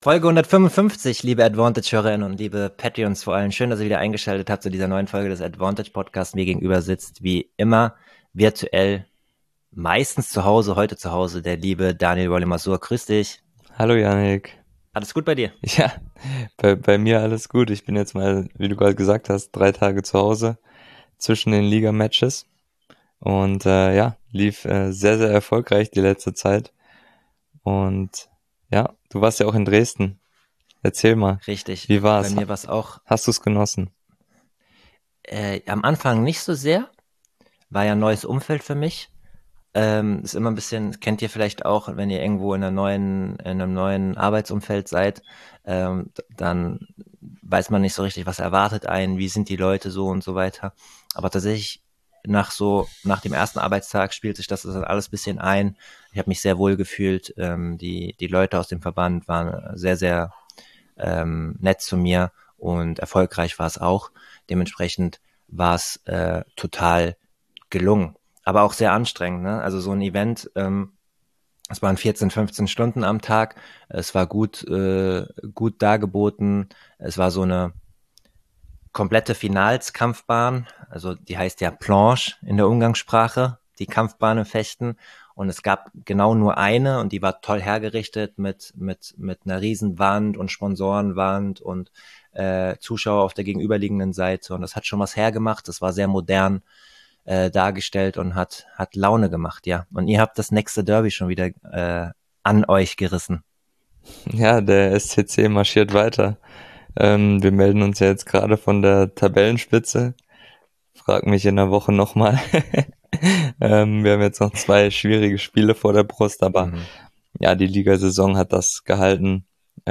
Hier kommen Persönlichkeiten aus dem Tennis und der weiteren Sportwelt in langen Karriereinterviews zu Wort, können aktuelle Themen ausführlich besprechen oder sensible und negativbehaftete Themen wie Doping-, Manipulation-, mentale Gesundheit und vieles mehr ausgeruht ansprechen.